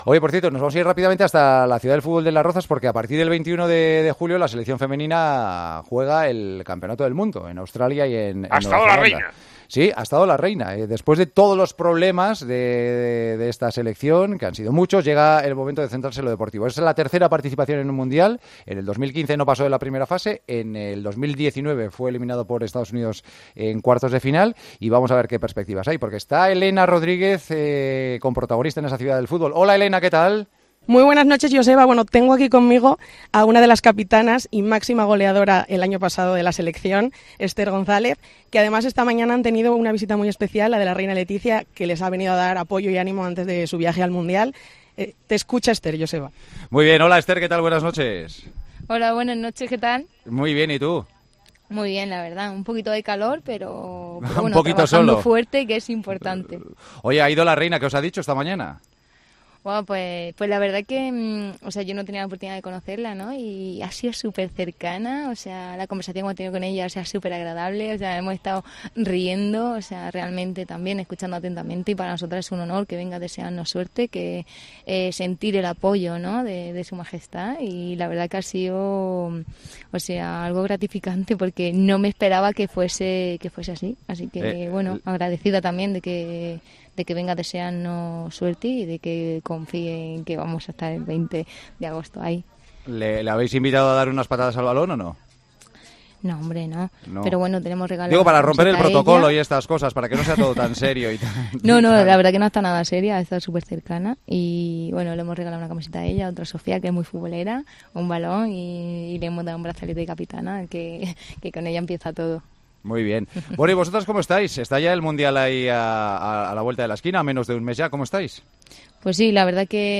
Hablamos en El Partidazo de COPE con la futbolista de la selección española desde la concentración de España antes del Mundial femenino tras su polémica salida del Real Madrid.